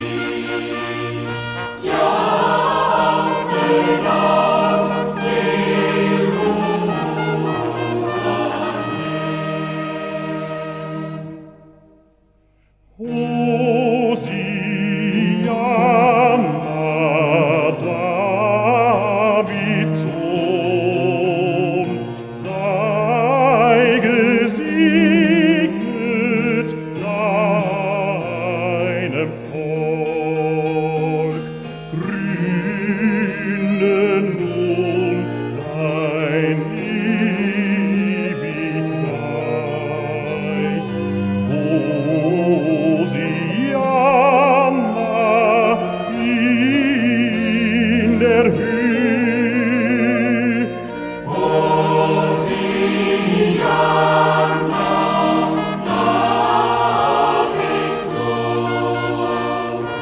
Chormusik/Evangeliumslieder